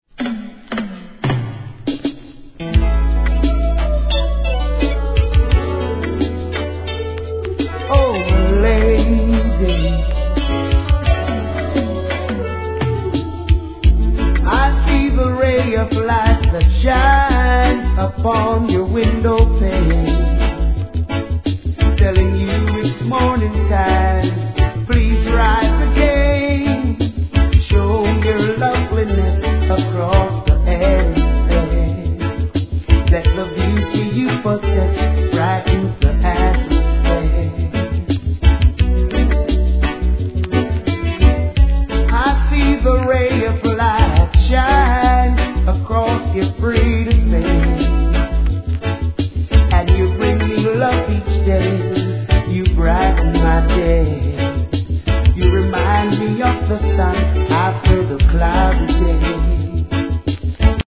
REGGAE
優しいヴォーカルが染みわたる逸品!!